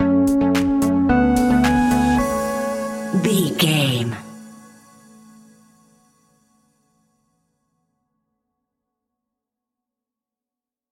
Ionian/Major
groovy
uplifting
driving
energetic
repetitive
synthesiser
drums
electric piano
electronic
electronic instrumentals